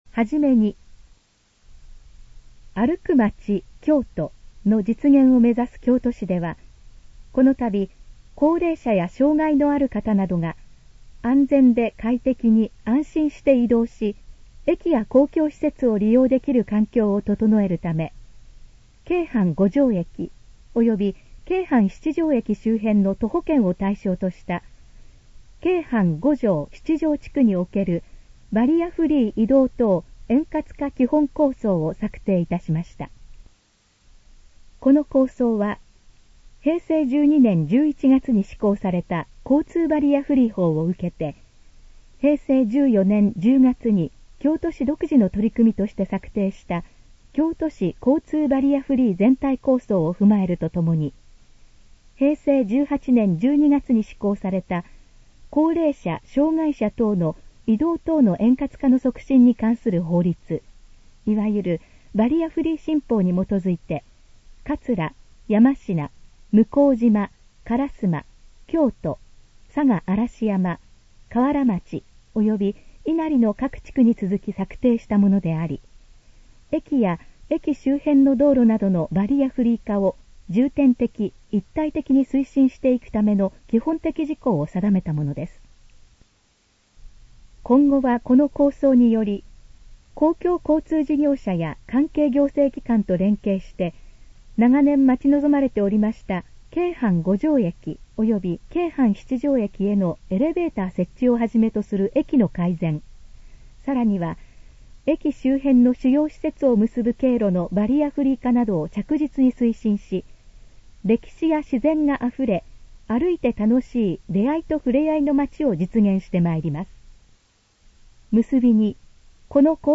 このページの要約を音声で読み上げます。